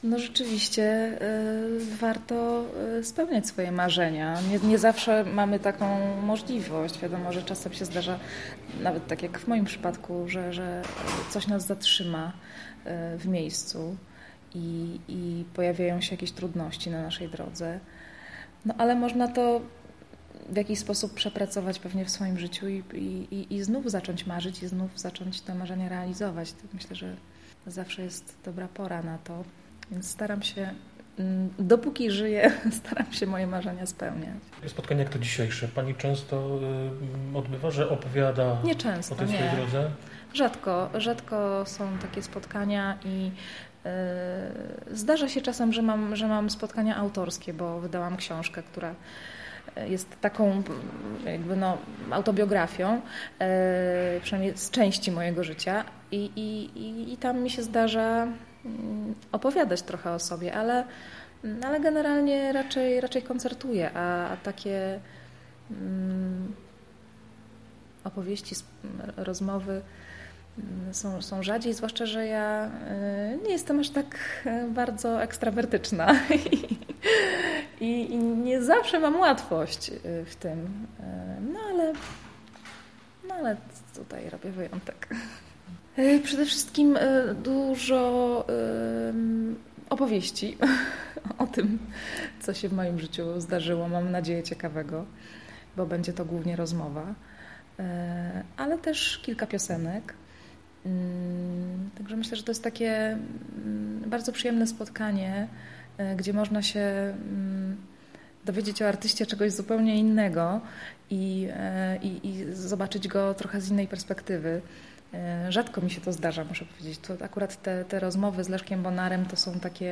Z Moniką Kuszyńską udało nam się porozmawiać zanim pojawiła się na scenie.